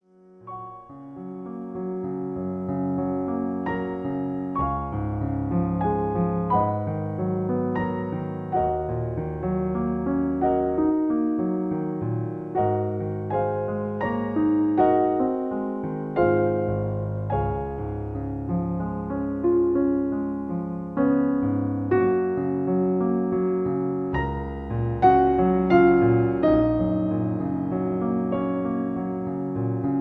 In G flat. Piano Accompaniment